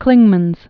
(klĭngmənz)